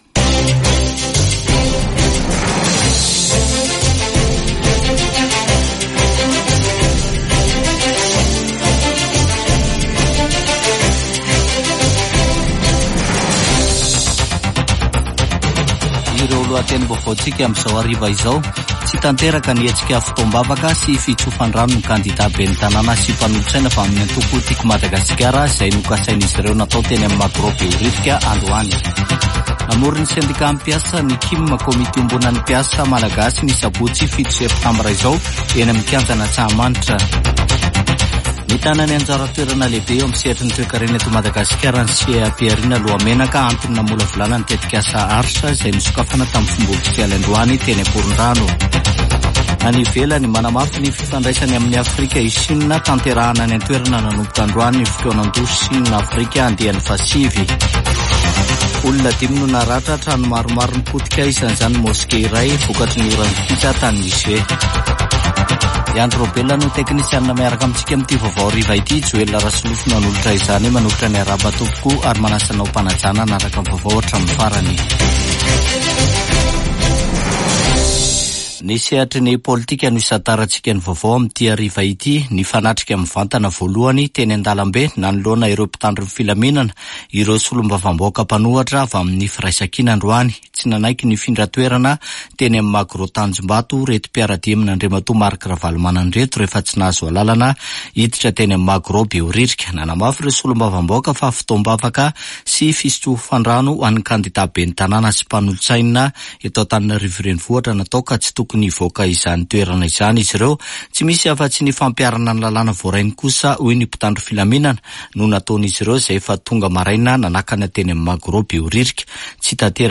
[Vaovao hariva] Alarobia 4 septambra 2024